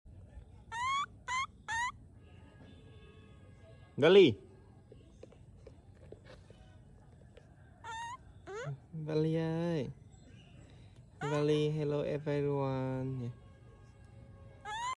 videos of suitcases making baby sound effects free download
videos of suitcases making baby monkey sounds.